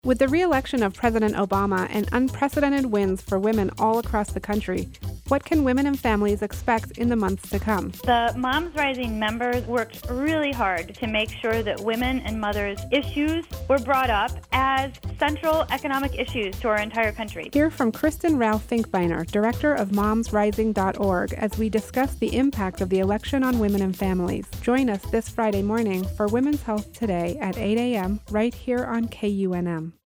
Women’s Health Today 11/9/12 Promo